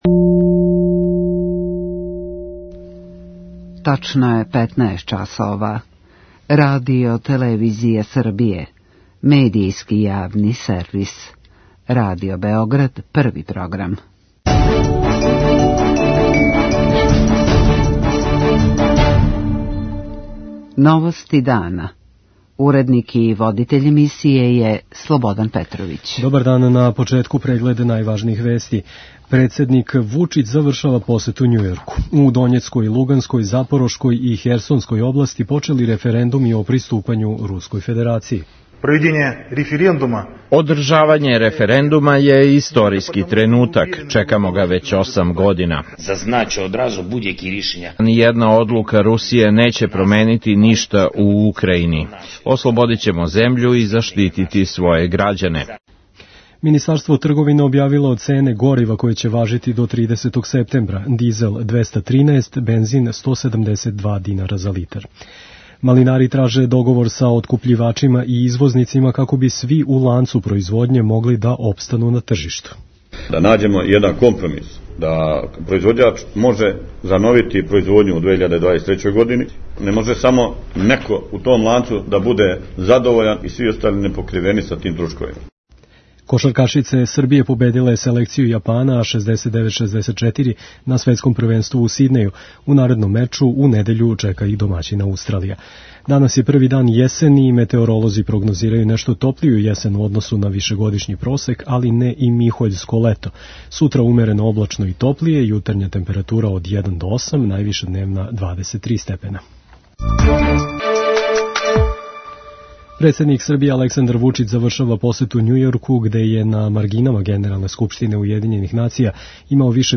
централна информативна емисија Првог програма Радио Београда